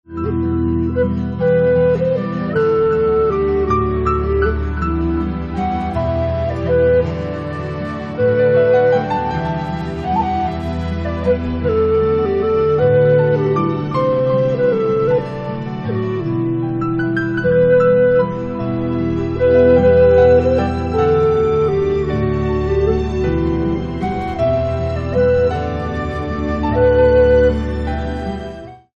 Celtic harp